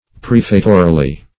prefatorily - definition of prefatorily - synonyms, pronunciation, spelling from Free Dictionary Search Result for " prefatorily" : The Collaborative International Dictionary of English v.0.48: Prefatorily \Pref"a*to*ri*ly\, adv. In a prefatory manner; by way of preface.